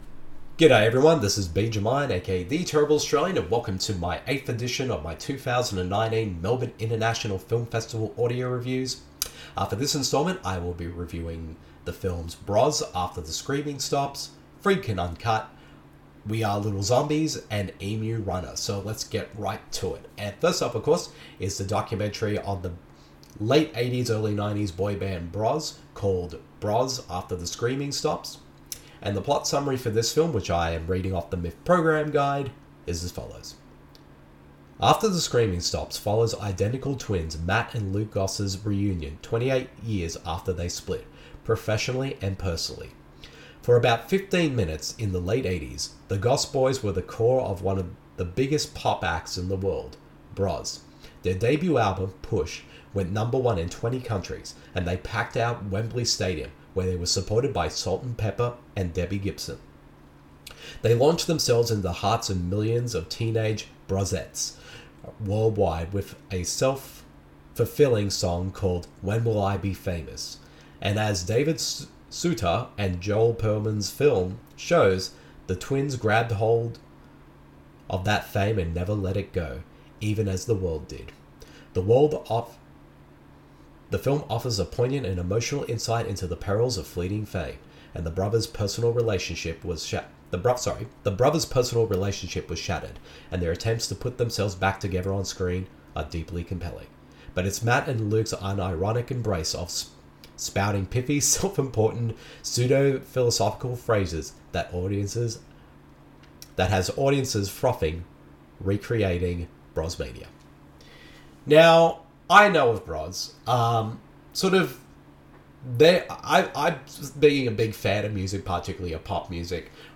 Audio reviews